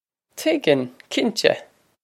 Pronunciation for how to say
Tig-in, keen-teh!
This is an approximate phonetic pronunciation of the phrase.